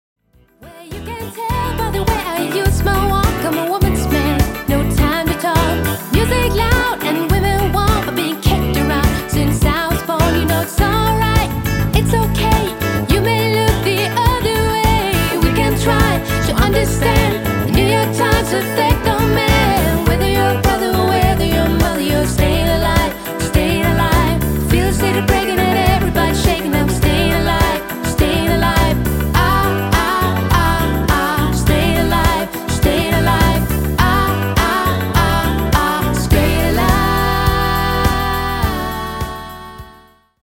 Dansemusik for alle aldre.
• Coverband